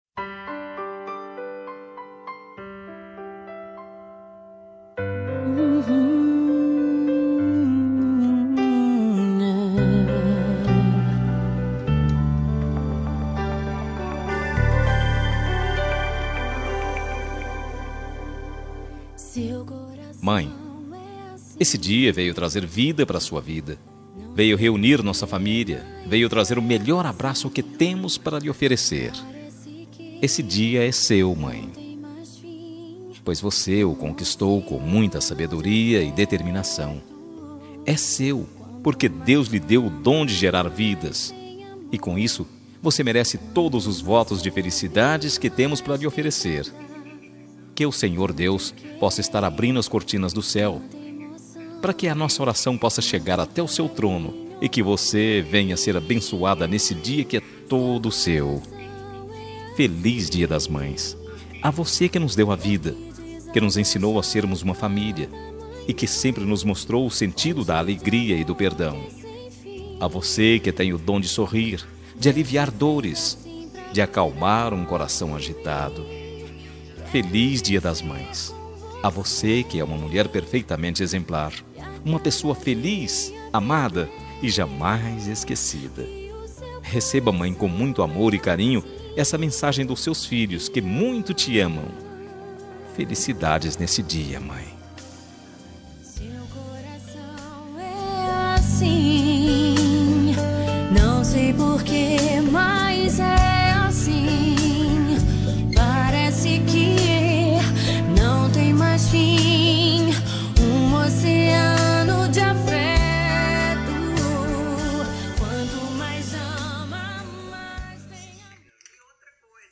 Dia das Mães – Para minha Mãe – Voz Masculina – Plural – Cód: 6525